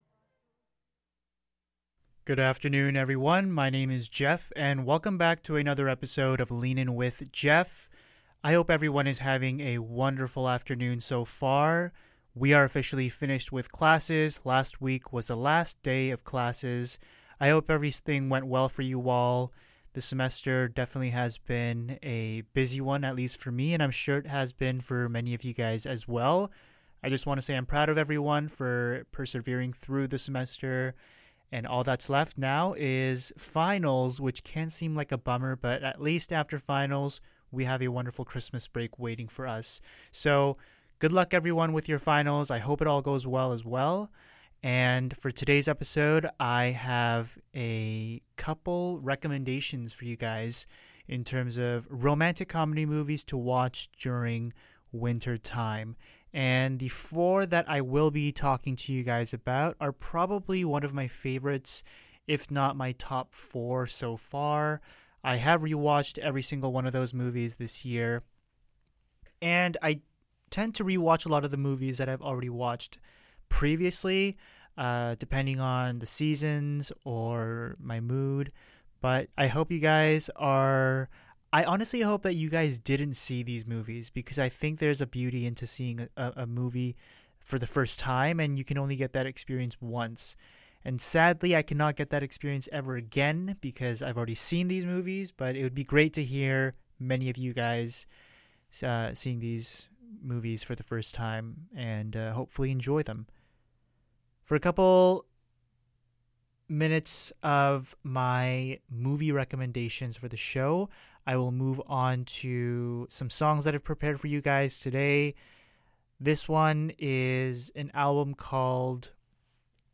From uplifting rhythms to introspective harmonies, discover how each song creates a unique sonic landscape that lingers long after the music stops. To wrap up, we’ll embrace the season with a flurry of fascinating facts about snow.